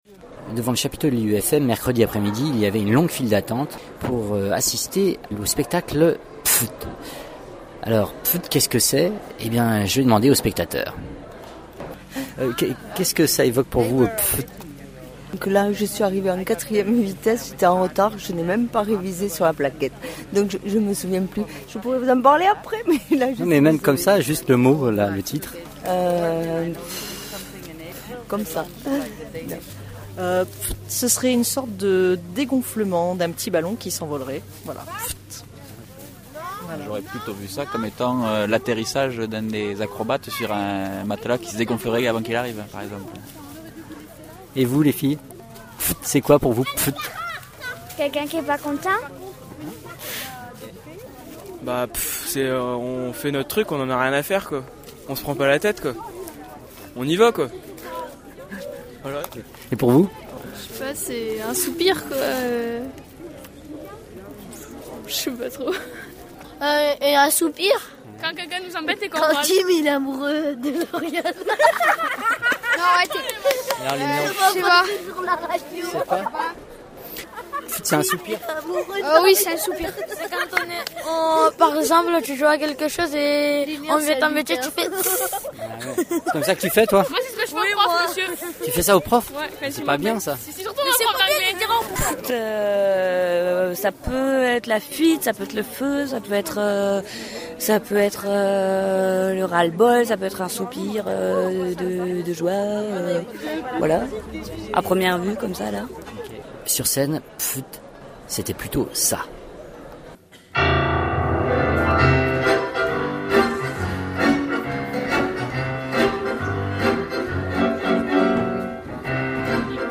reportage_spectacle_pffft-pad.mp3